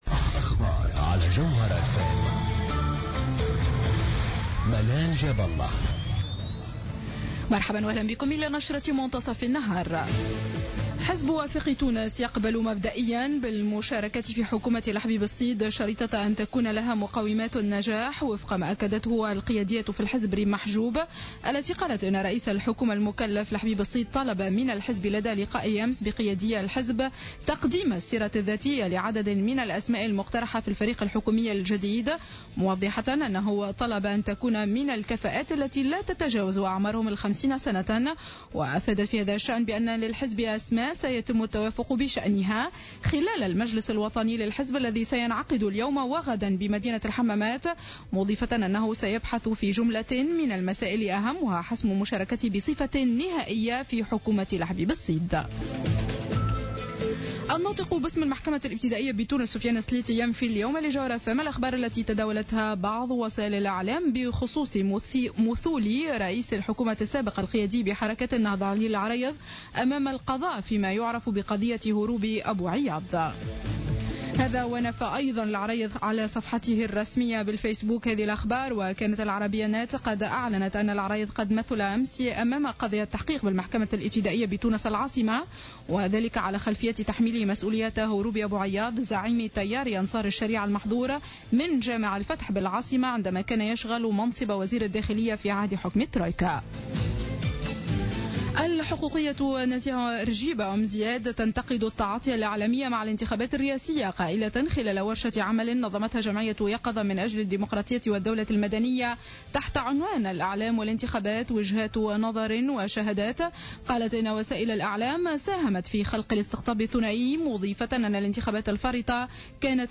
نشرة أخبار منتصف النهار ليوم السبت 17-01-15